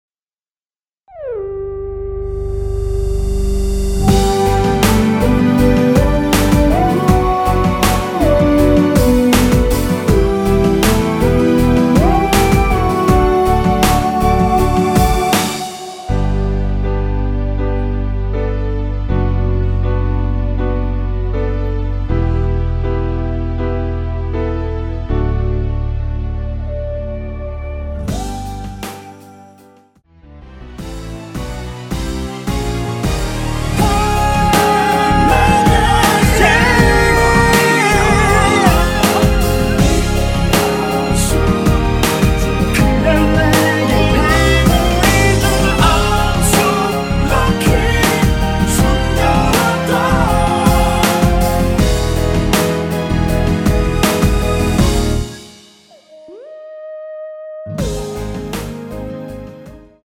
원키에서 (-2)내린 코러스 포함된 MR입니다.
앞부분30초, 뒷부분30초씩 편집해서 올려 드리고 있습니다.